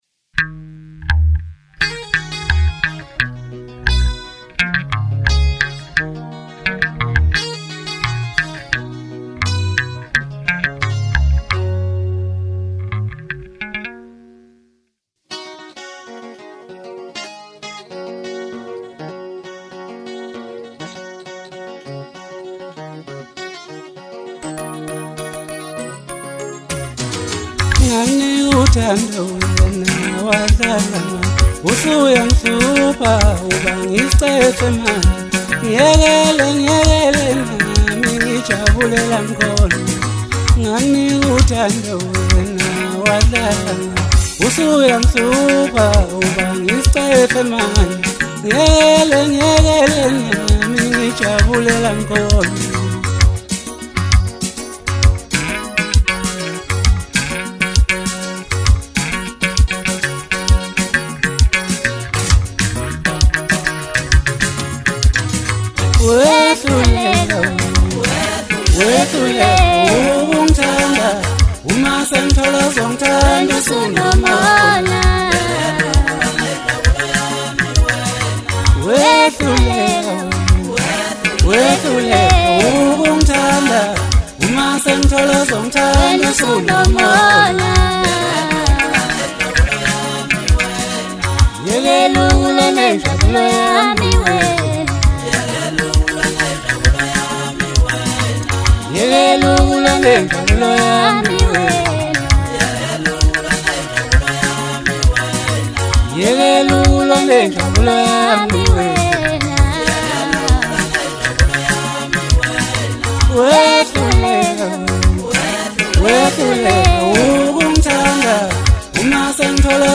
Genre : Masakndi